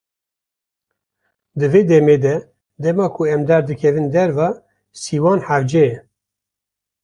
Pronounced as (IPA) /siːˈwɑːn/